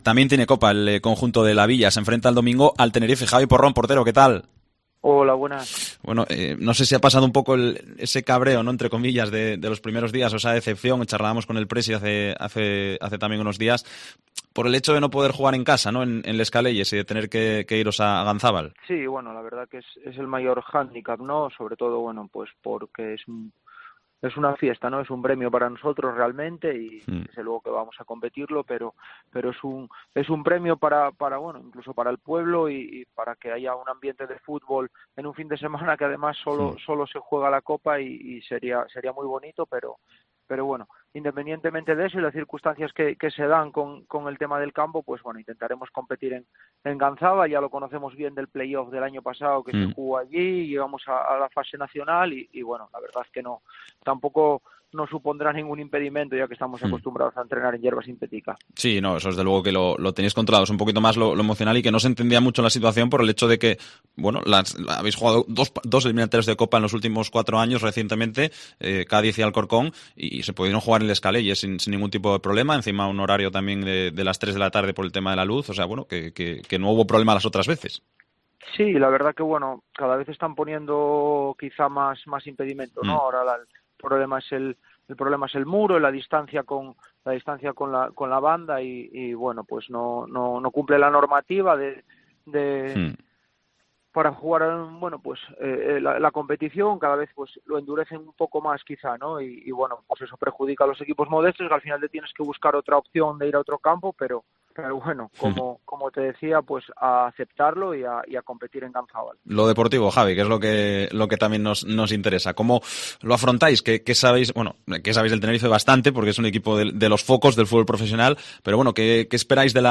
Atiende la llamada de Deportes COPE Asturias.